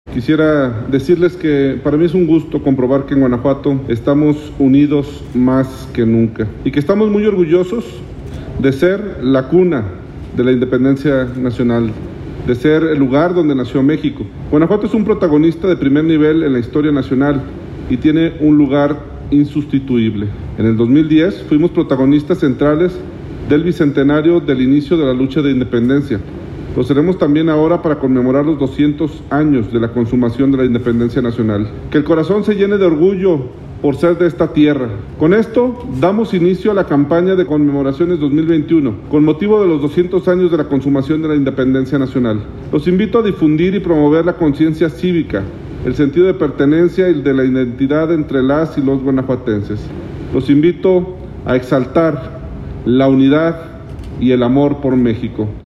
25.-Junio-Mensaje-del-Gobernador-Diego-Sinhue-Lanzamiento-Campaña-200-años-Conmemoración-Independencia.mp3